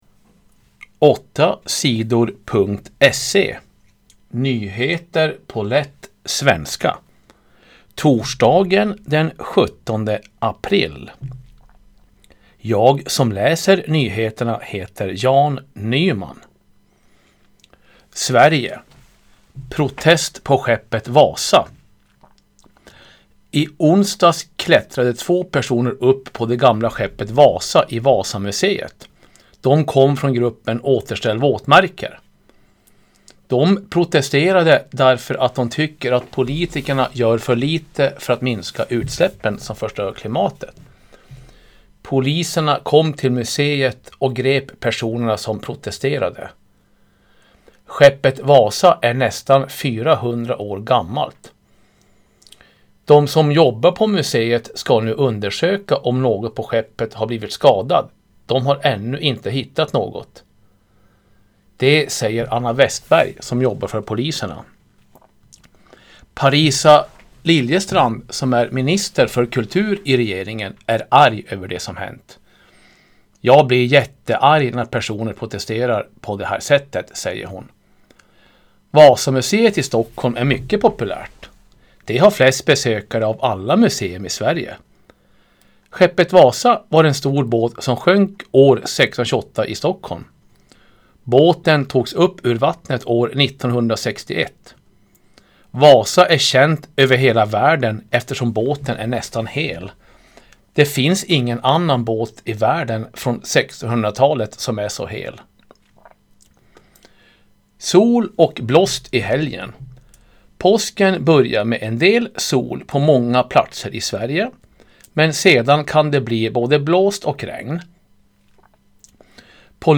Nyheter på lätt svenska den 31 januari.
- lättlästa nyheter … continue reading 28 επεισόδια # Lyssna 8 Sidor # News Talk # Nyheter # 8 Sidor